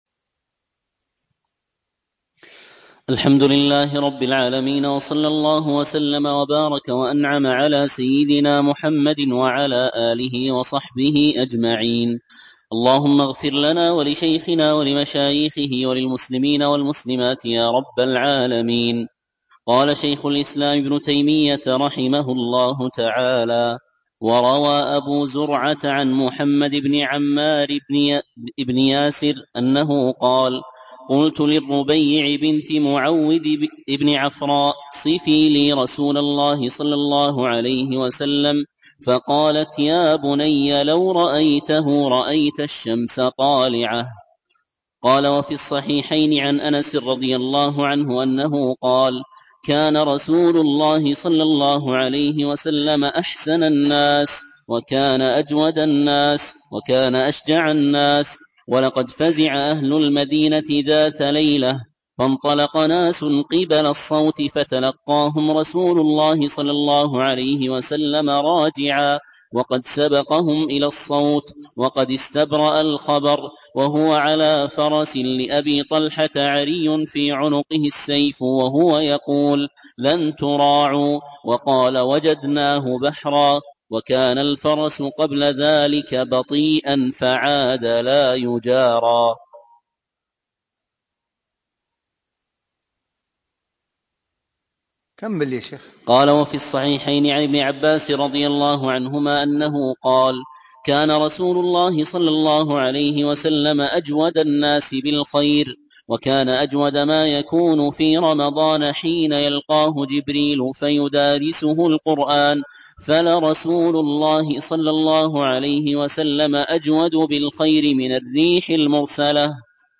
يوم الاربعاء 7 شعبان 1438 الموافق 3 5 2017 في مسجد عائشة المحري المسائل